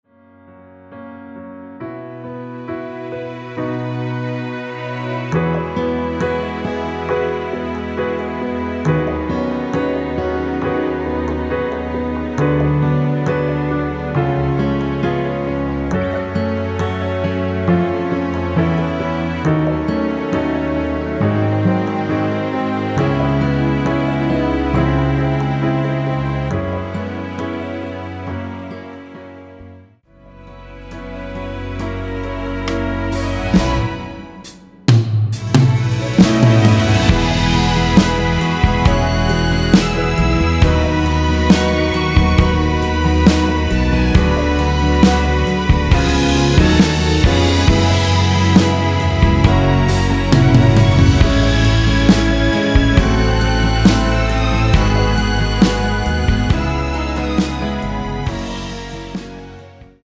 원키 MR입니다.
전주가 길어서 미리듣기는 중간 부분 30초씩 나눠서 올렸습니다.
중간에 음이 끈어지고 다시 나오는 이유는